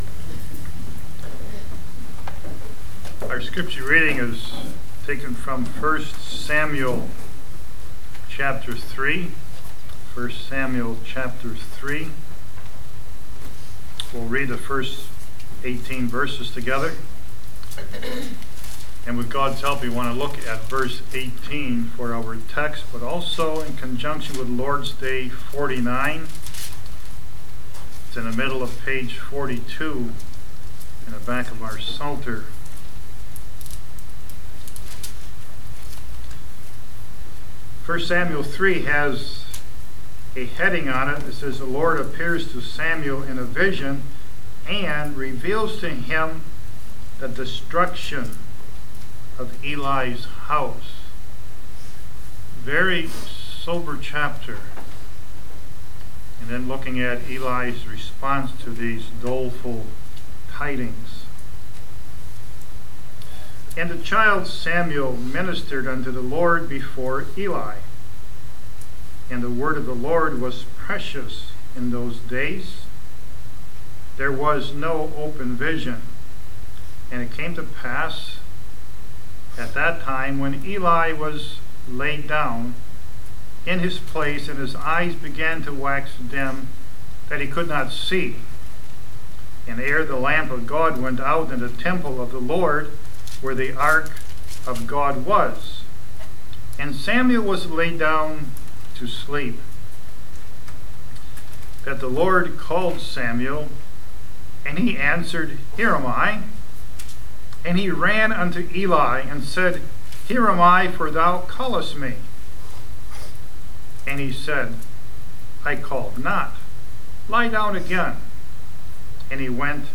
New Hope Reformed Fellowship Church Sermon_Audios